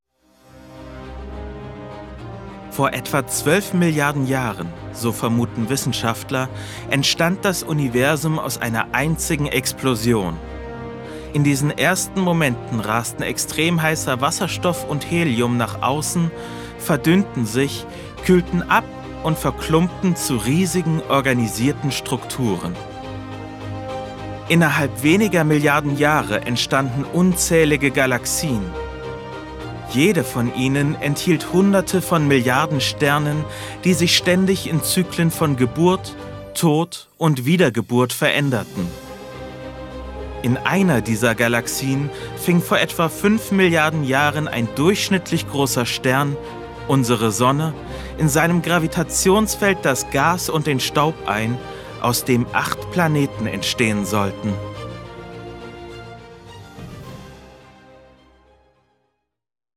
Sprachproben
Sprecher, Werbesprecher